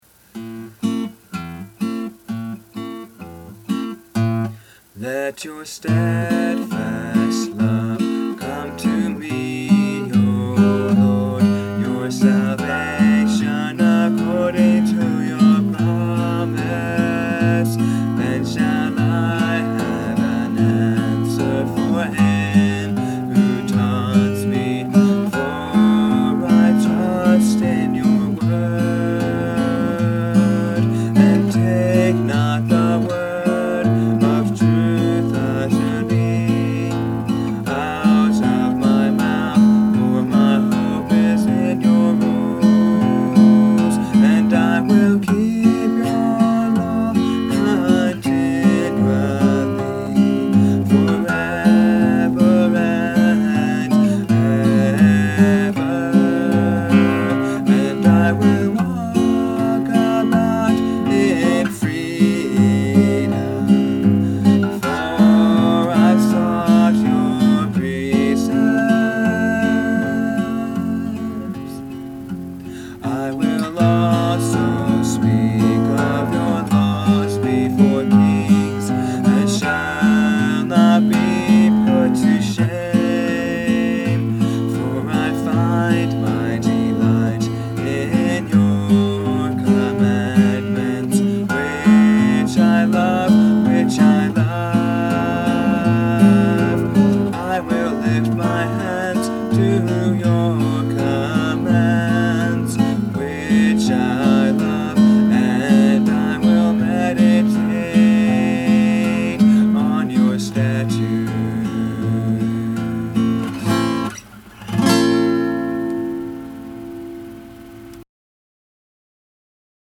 I hope to make some cleaner sounding recordings later, but I thought I would post the rough recordings I have now.